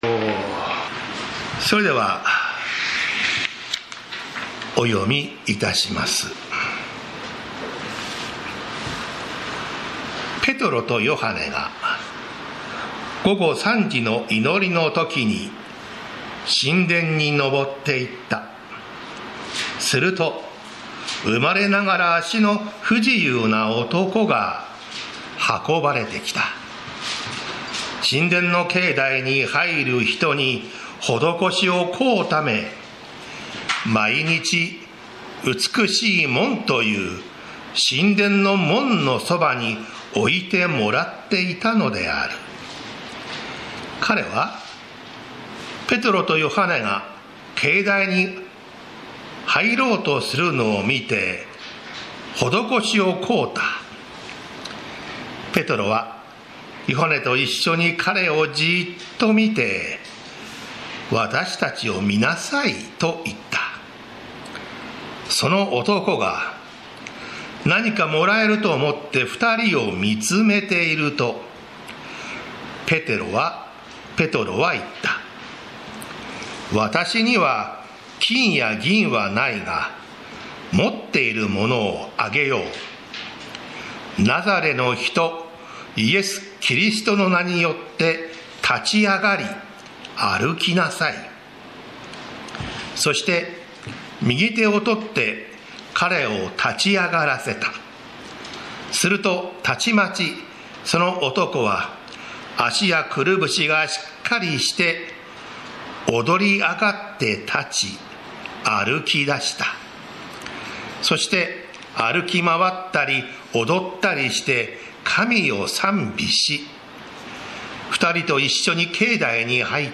栃木県鹿沼市 宇都宮教会
礼拝説教アーカイブ